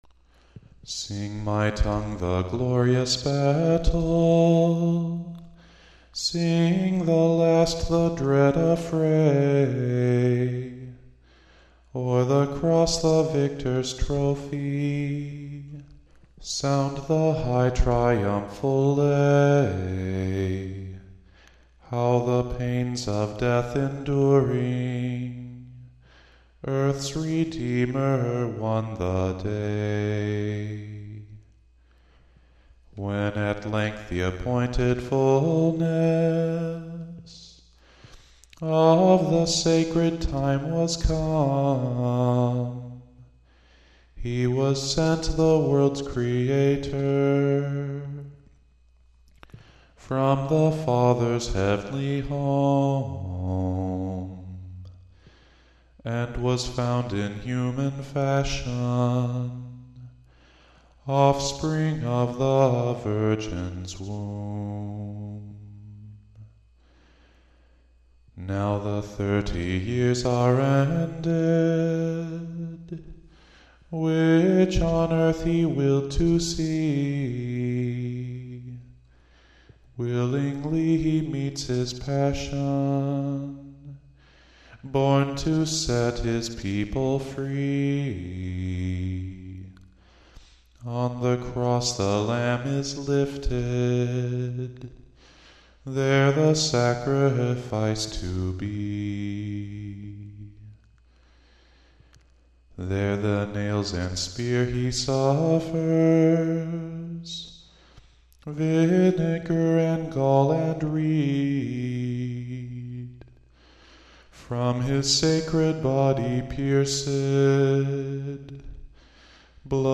Hymn for Judica chanted.